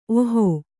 ♪ ohō